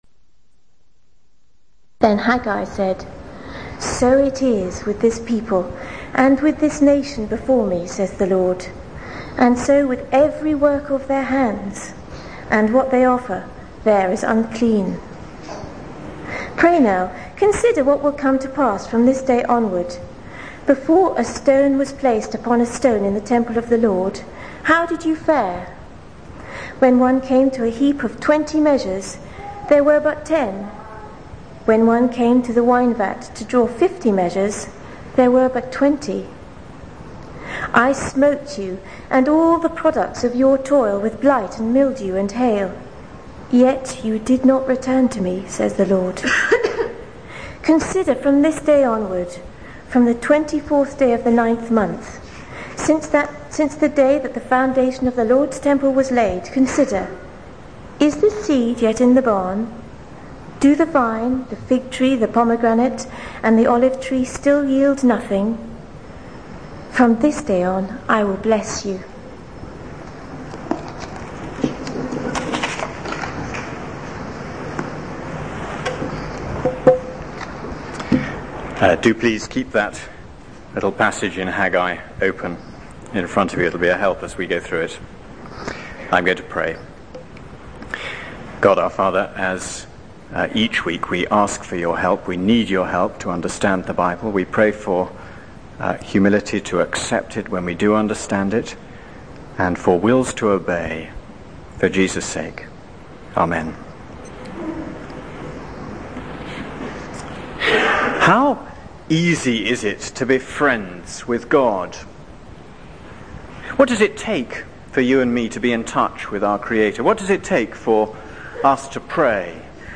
This is a sermon on Haggai 2:10-19.